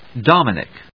音節Dom・i・nic 発音記号・読み方
/dάmənɪk(米国英語), dˈɔmənɪk(英国英語)/